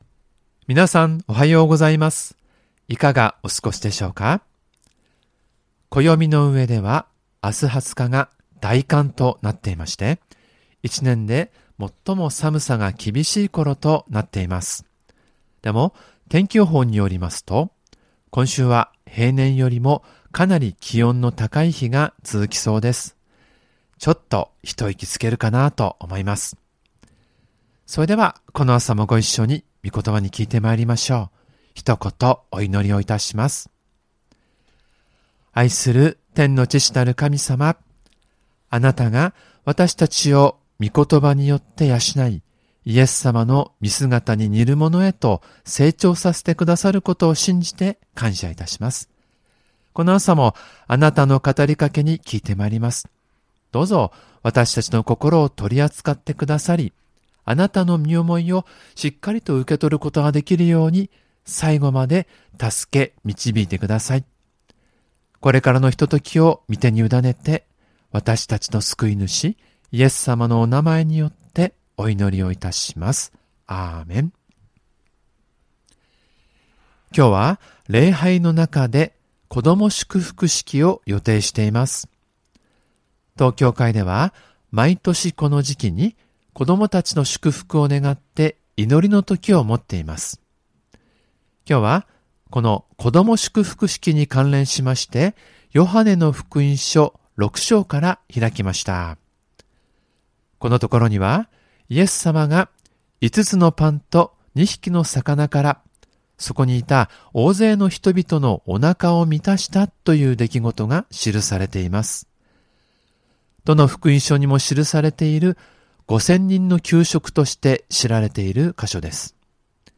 ●主日礼拝メッセージ（赤文字をクリックするとメッセージが聴けます。MP3ファイル）